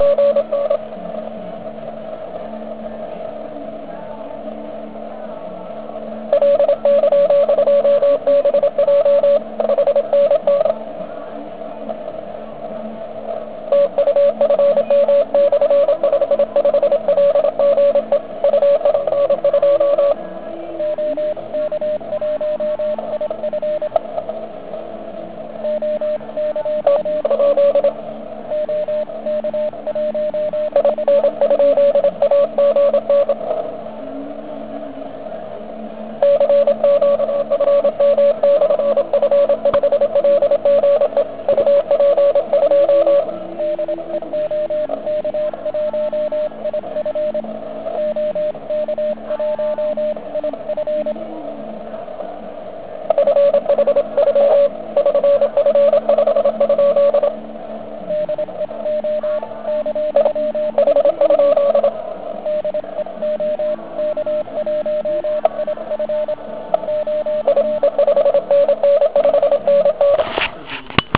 Kdy� to hraje tak siln�, pak je jasn�, �e �tlum n�kde se zmen�il.
Dob�e. Ta muzika v pozad� je z r�dia vedle sri.